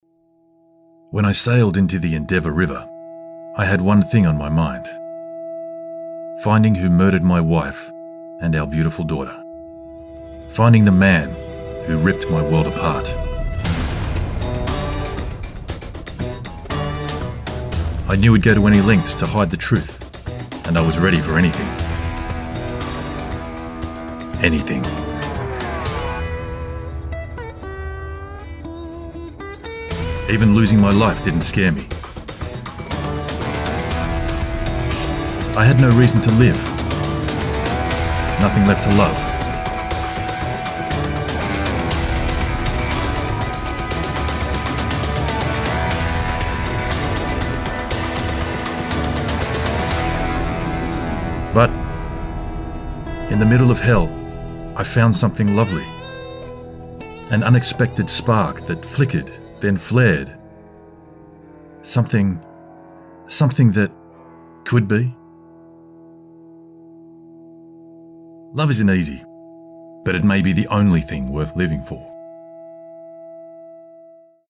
Inglês (australiano)
Audiolivros
A mistura perfeita de leitura casual, um ar de autoridade e um sorriso que você pode ouvir?
BarítonoGravesContraltoProfundoBaixo